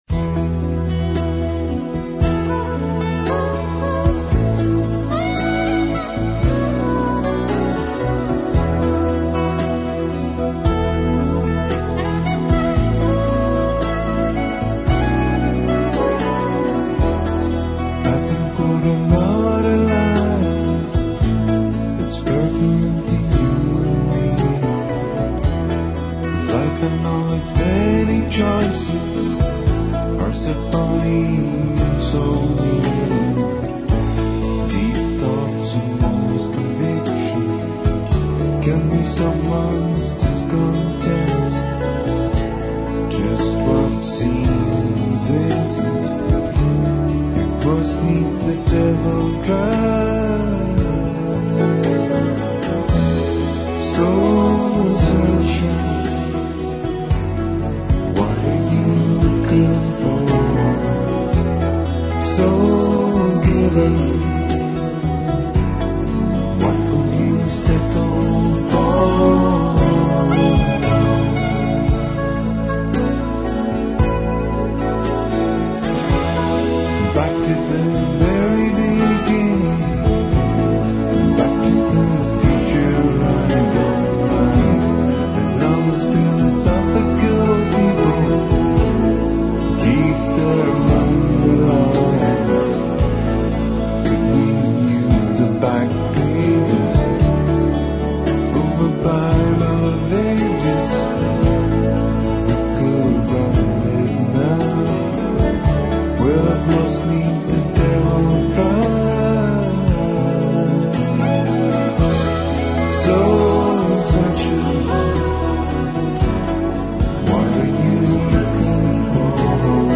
03 - SOUL